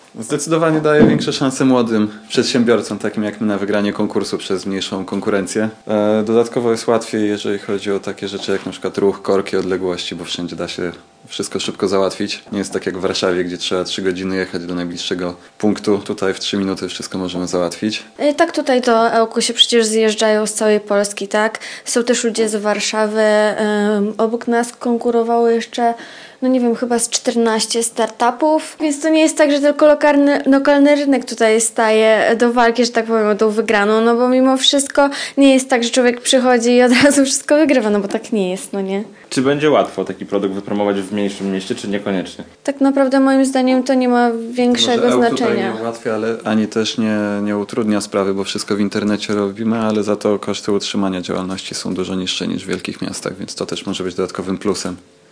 Spotkanie przedstawicieli biznesu i samorządowców odbyło się w Parku Naukowo-Technologicznym w Ełku.